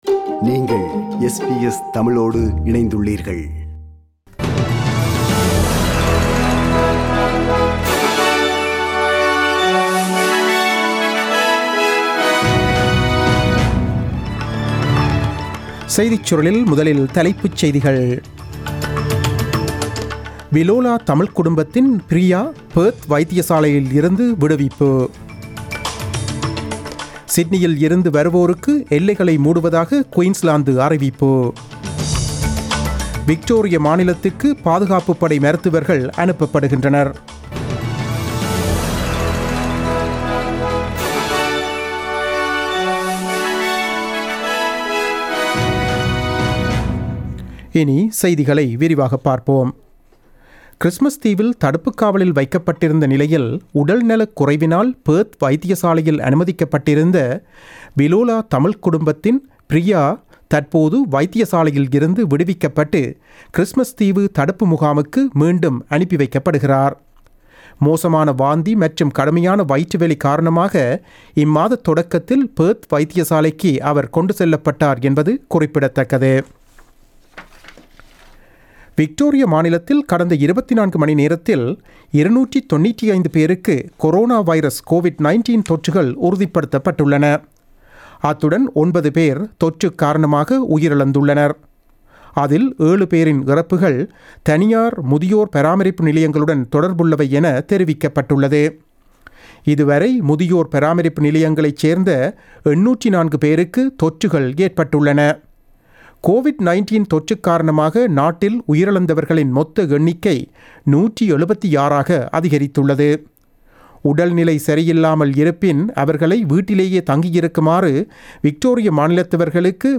The news bulletin broadcasted on 29 July 2020 at 8pm.